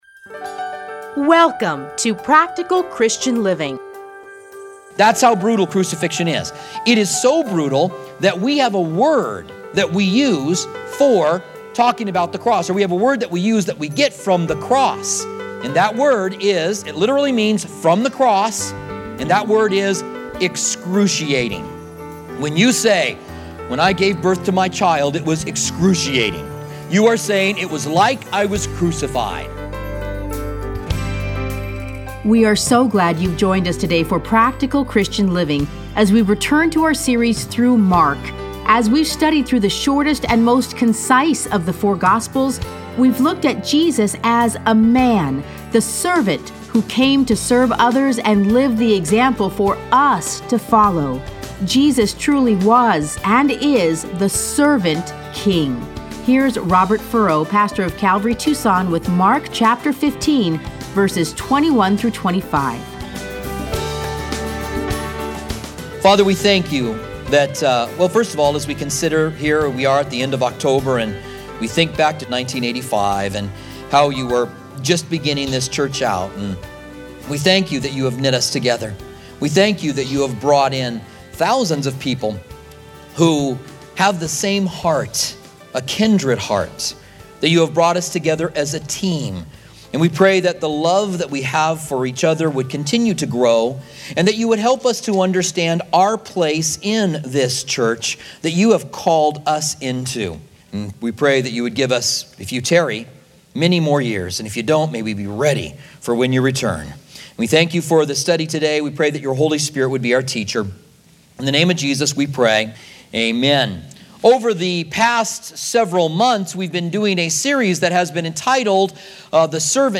Listen to a teaching from Mark 15:21-25.